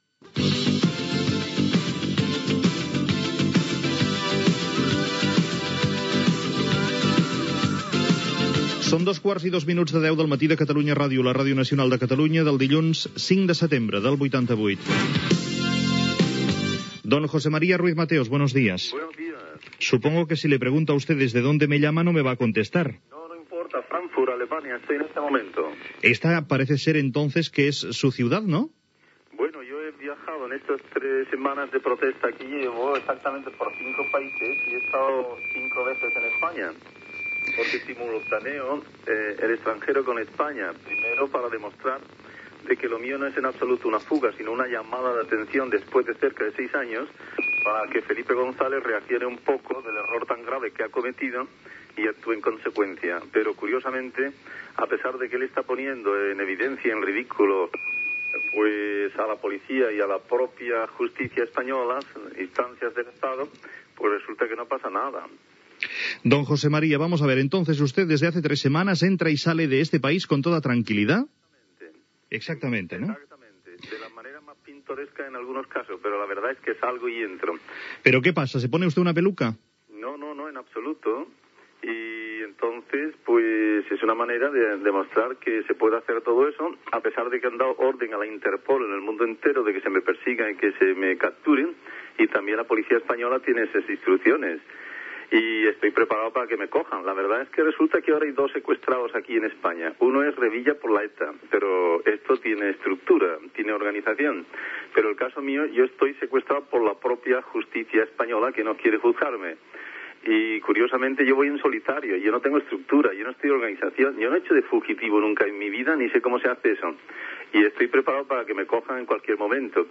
Hora, identificació del programa, data, entrevista a l'empresari José María Ruiz-Mateos que es troba a Frankfurt fugat de la justícia espanyola.
Informatiu
FM